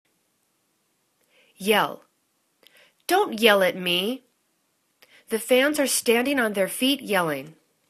yell     /yel/    v